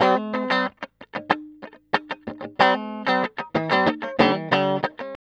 TELEDUAL C#3.wav